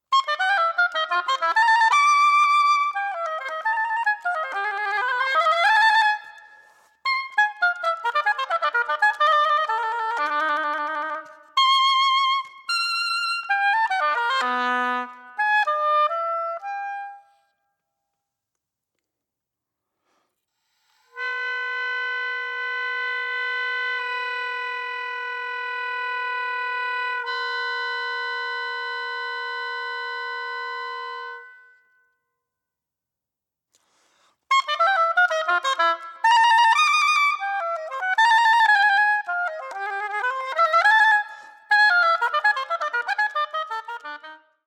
oboe
clarinetts
saxophones
trumpet
guitar
percussion
piano